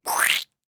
splat-v7.ogg